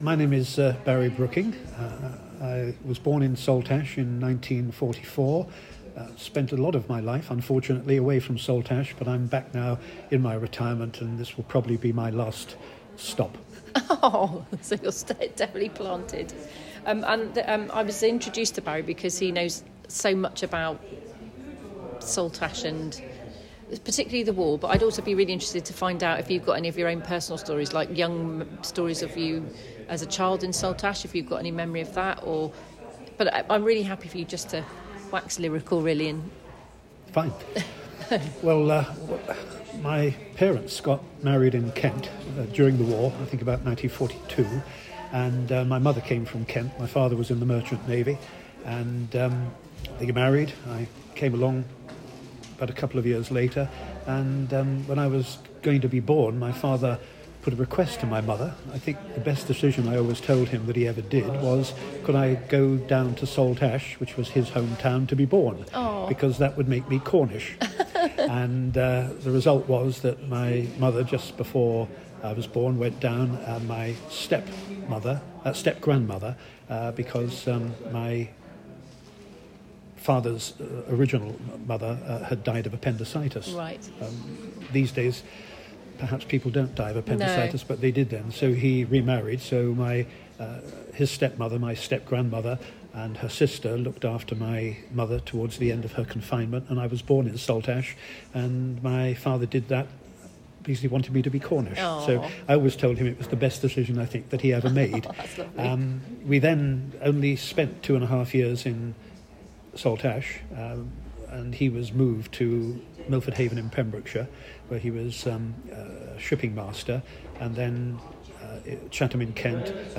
In this compelling oral history